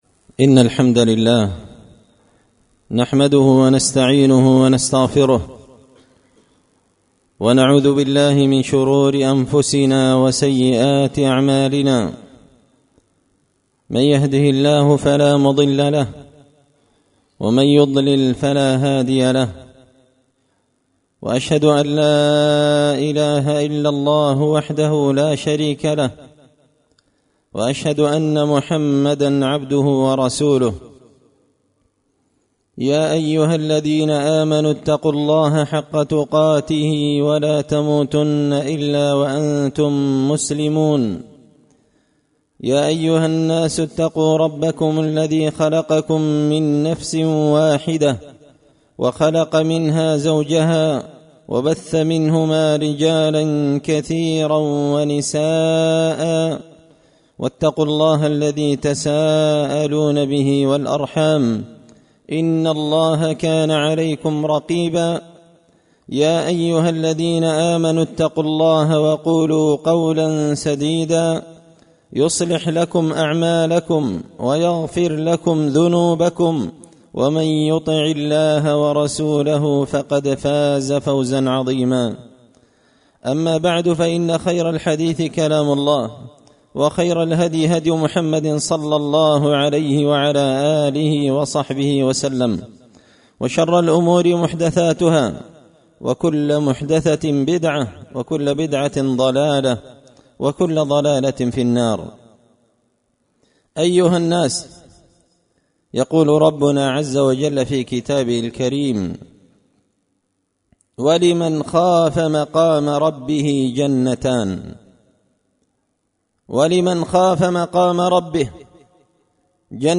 خطبة جمعة بعنوان – ولمن خاف مقام ربه جنتان
دار الحديث بمسجد الفرقان ـ قشن ـ المهرة ـ اليمن